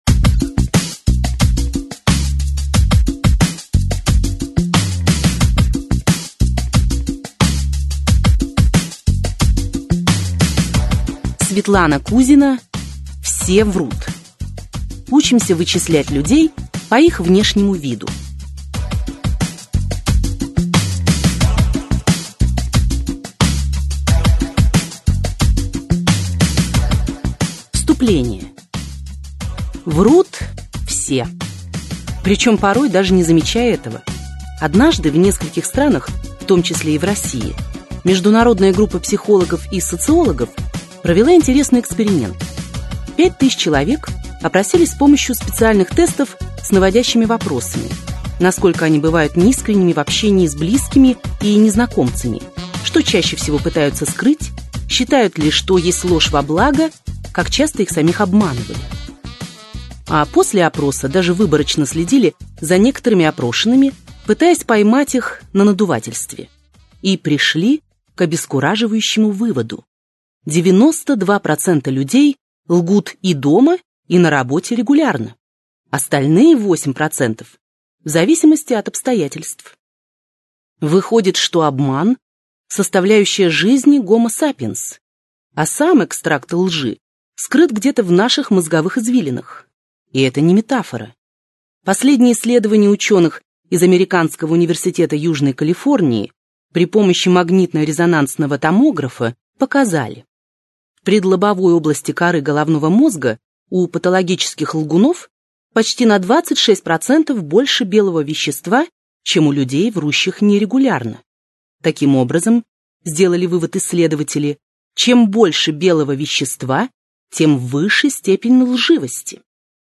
Аудиокнига Все врут! Учимся вычислять людей по их внешнему виду!